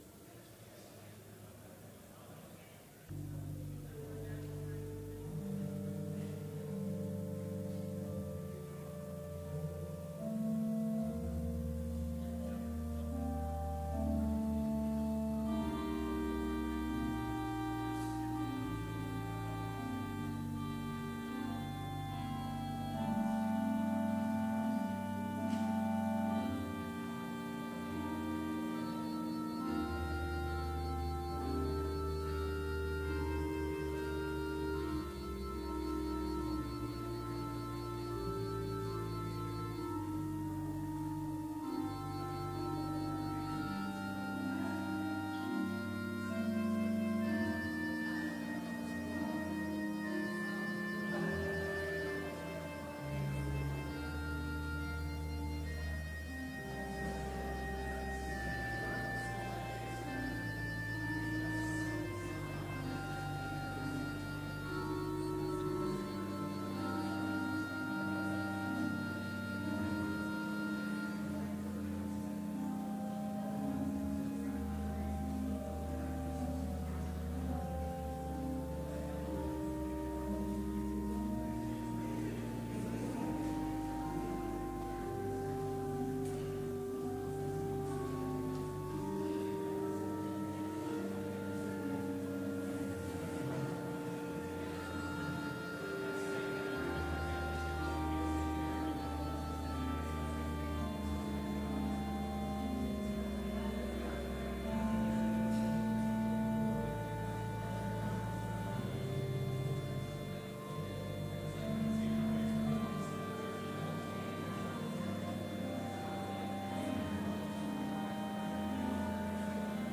Complete service audio for Chapel - April 17, 2019